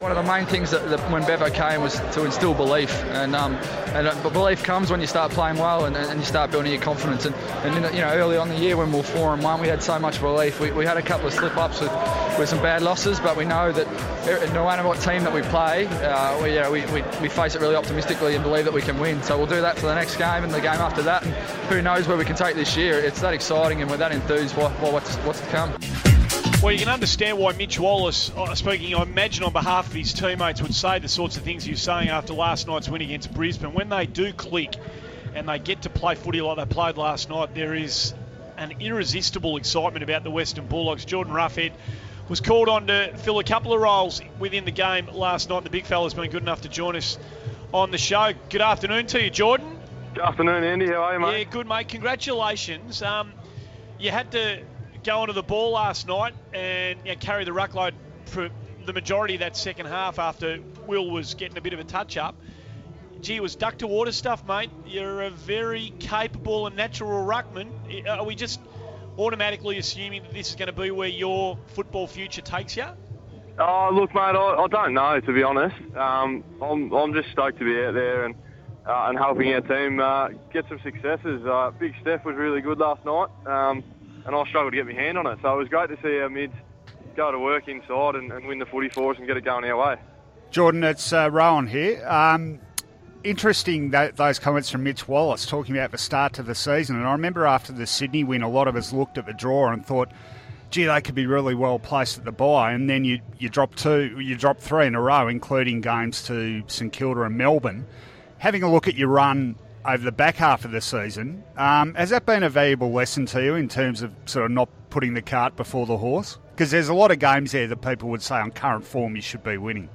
Western Bulldogs ruckman Jordan Roughead chats with Andy Maher, Scott Lucas, Nathan Thompson and Rohan Connolly following the Dogs' strong win over Brisbane.